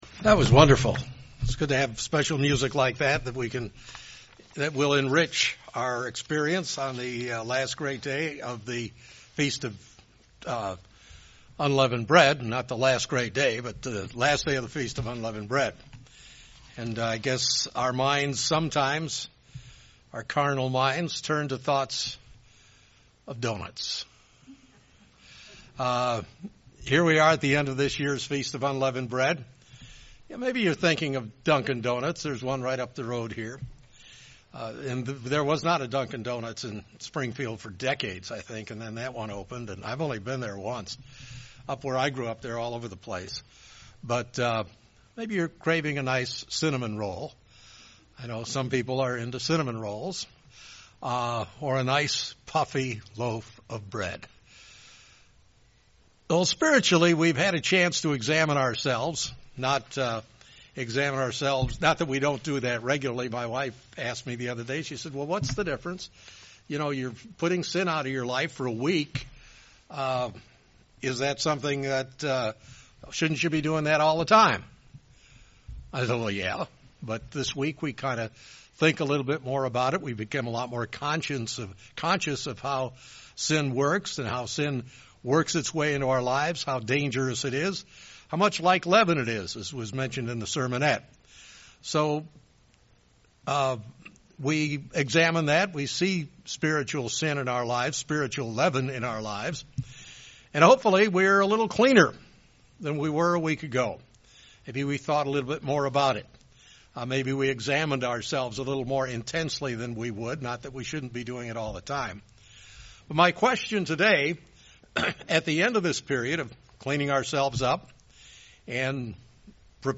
During the Last Day of Unleavened Bread, this sermon looks into the concepts learned throughout these days and where we can go from there.